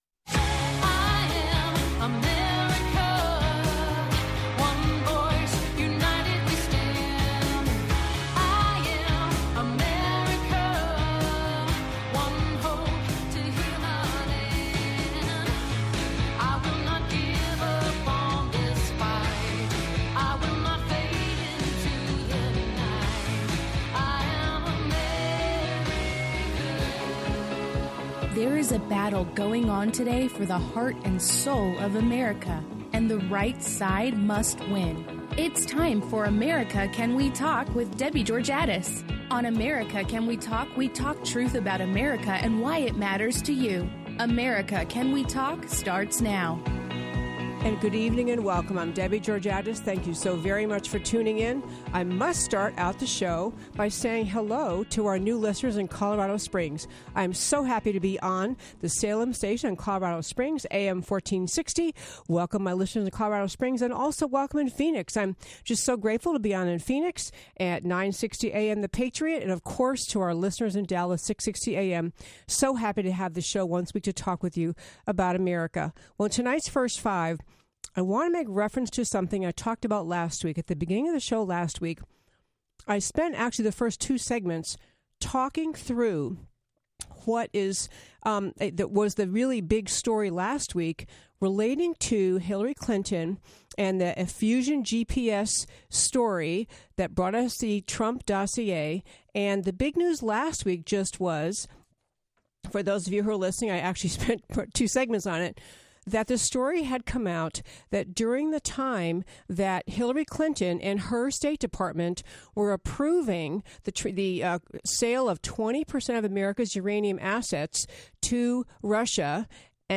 Listen to the first hour of our October 29th show here.